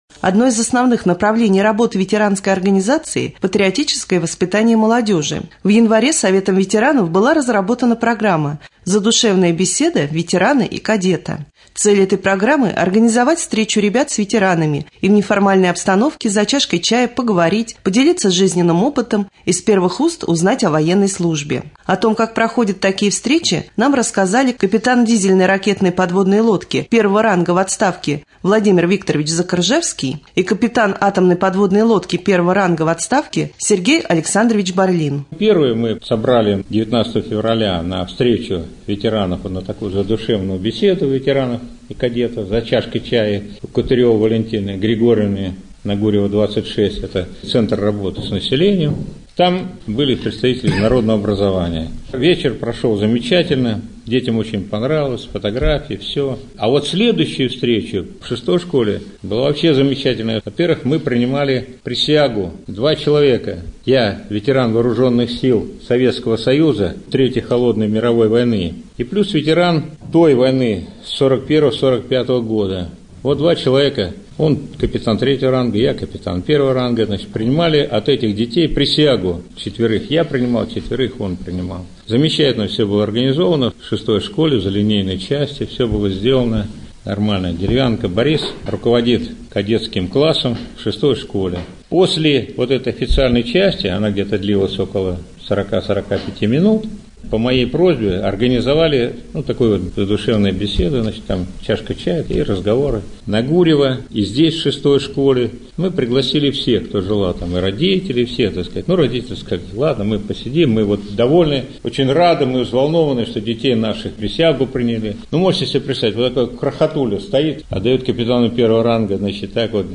23.04.2013г. в эфире раменского радио - РамМедиа - Раменский муниципальный округ - Раменское